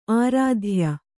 ♪ ārādhya